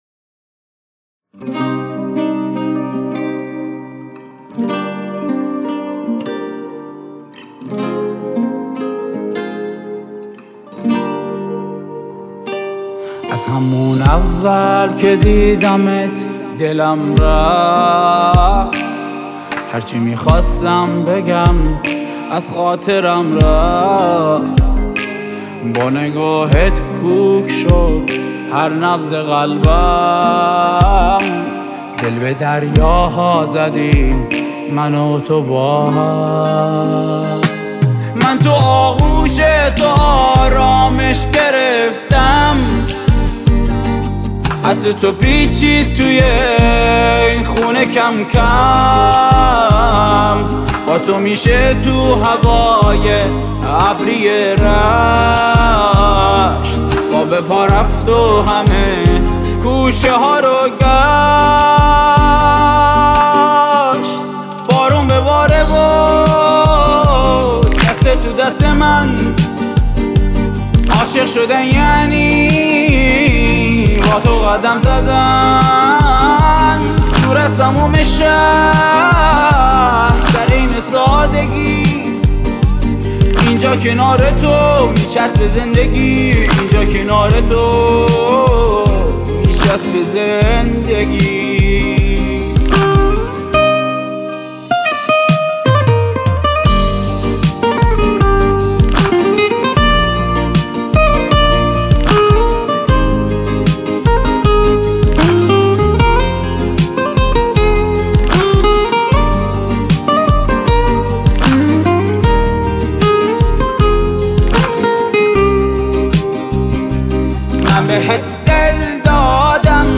موضوعات: تک آهنگ, دانلود آهنگ پاپ